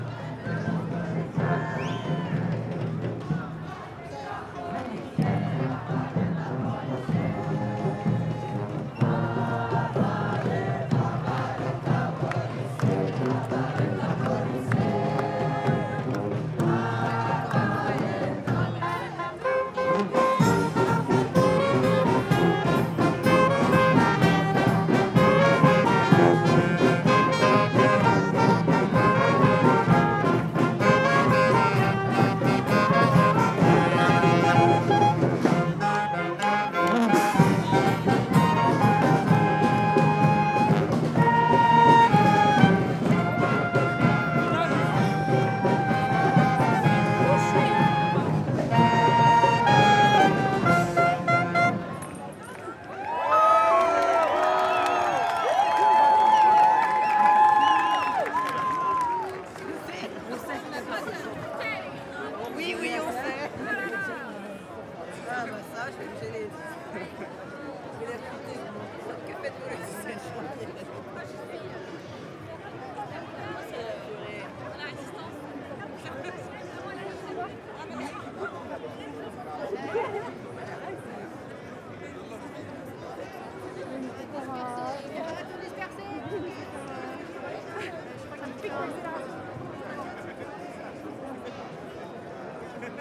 Demonstration in Paris. It involves singing with a marching band.
UCS Category: Ambience / Protest (AMBPrtst)
Type: Soundscape
Channels: Stereo
Disposition: ORTF
Conditions: Outdoor
Realism: Realistic
Equipment: SoundDevices MixPre-3 + Neumann KM184